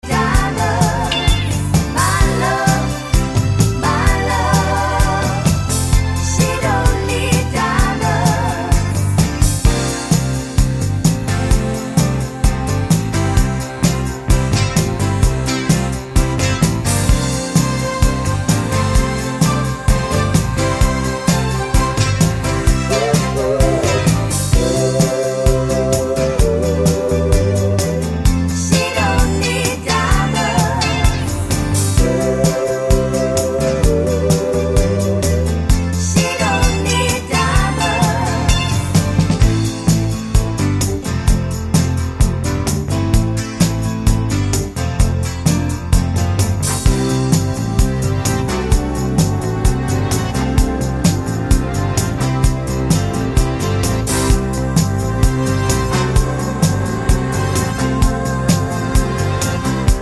no Backing Vocals Soft Rock 3:37 Buy £1.50